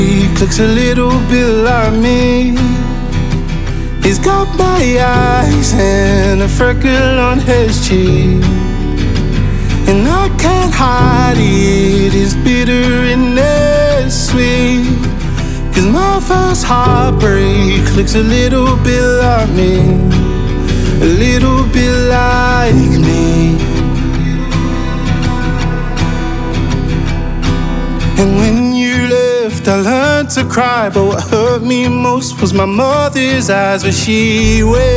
Скачать припев
Singer Songwriter